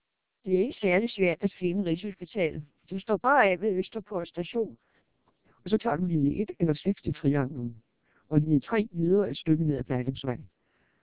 Speech Samples (WAV-files).
All independent listeners preferred the TWELP vocoder, noting its superior quality, clarity, naturalness, and speech intelligibility.